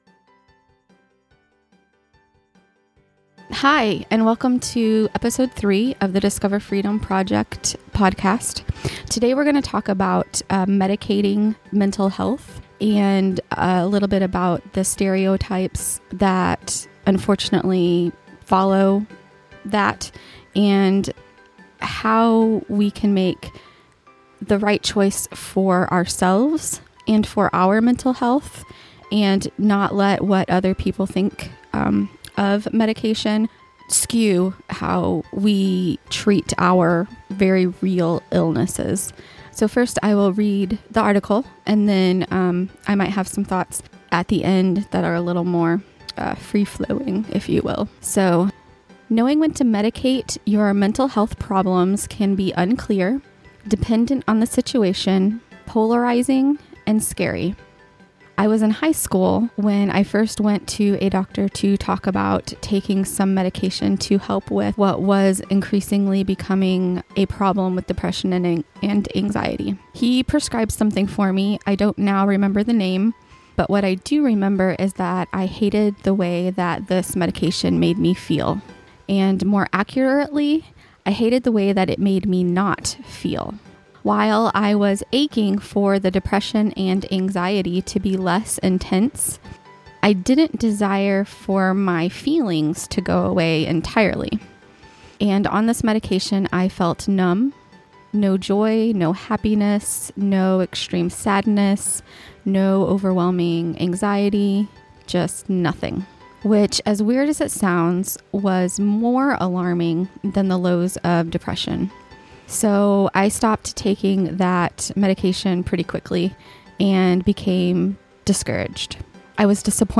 Summary: I read a blog post that shares my journey with taking medication for Depression and Anxiety. I guide you through some questions you can ask yourself regarding your readiness to talk about using medication to control your mental health and we discuss some of the stereotypes that come with Mental Health medications.